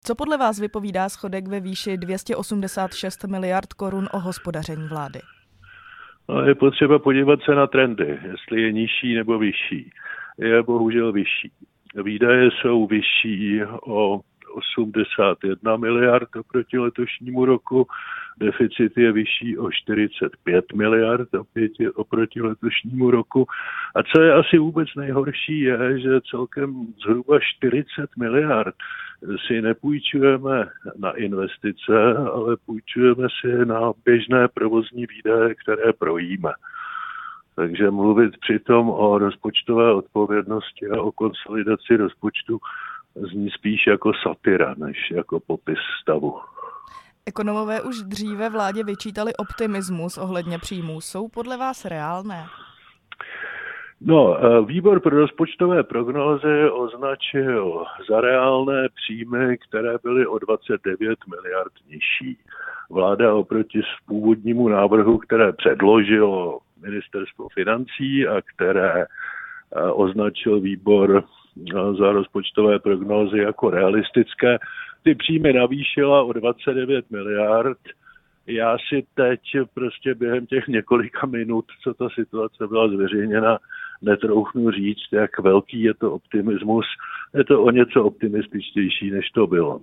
Rozhovor s exministrem financí Miroslavem Kalousekm